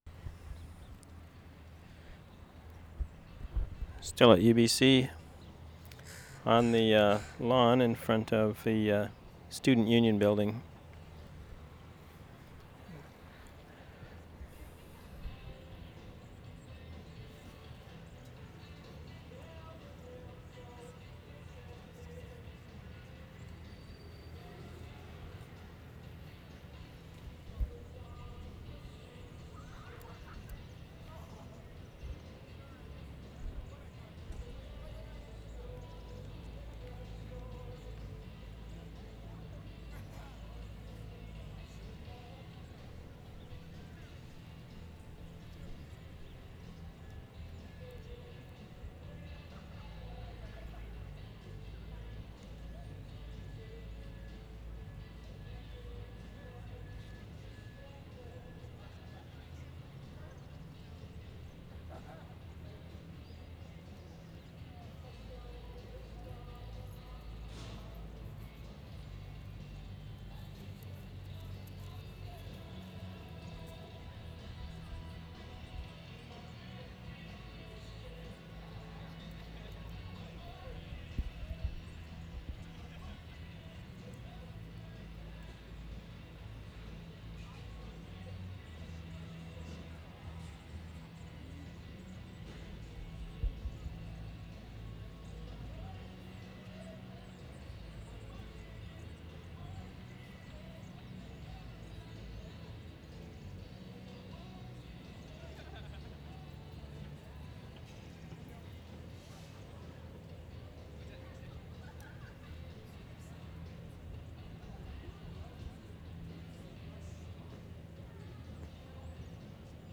WORLD SOUNDSCAPE PROJECT TAPE LIBRARY
VANCOUVER, WEST SIDE , SEPT 9-10, 1993
lawn in front of SUB building 5:37